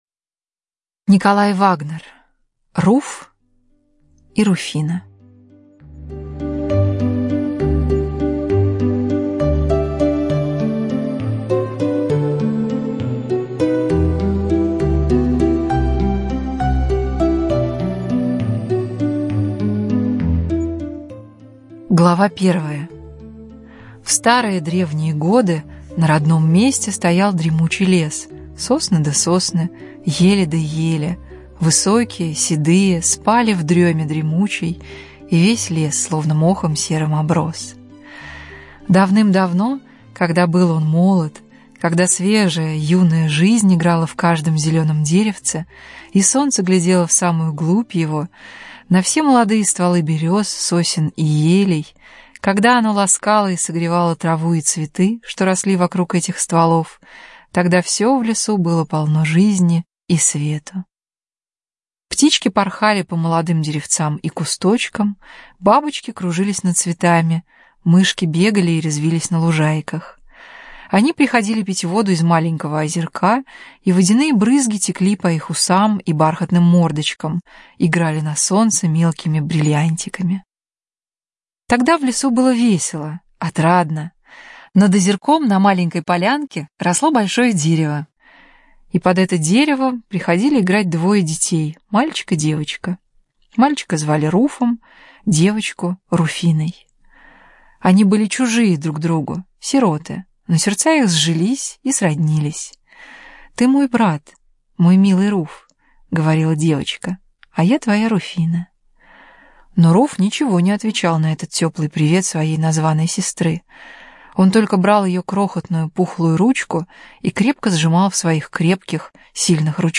Руф и Руфина - аудиосказка Вагнера - слушать онлайн